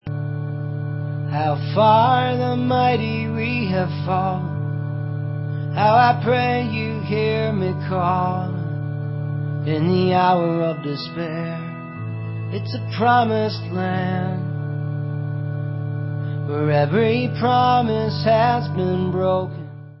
sledovat novinky v kategorii Rock